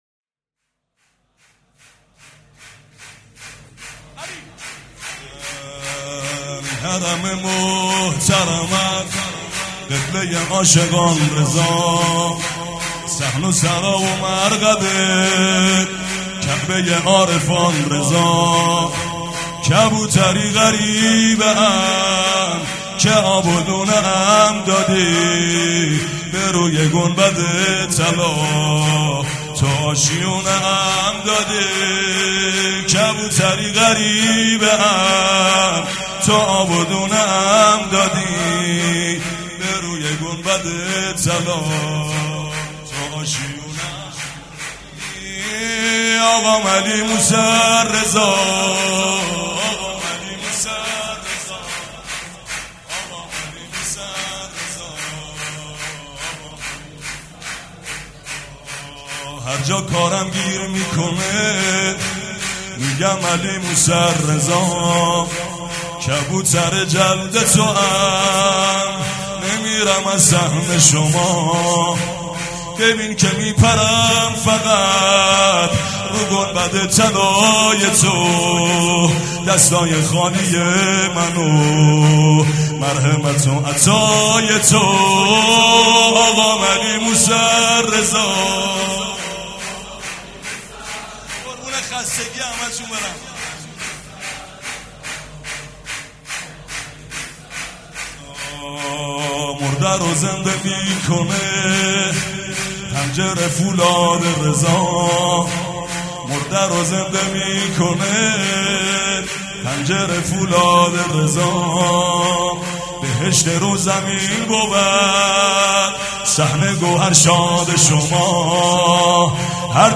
«میلاد امام رضا 1397» شور: کبوتری غریبه ام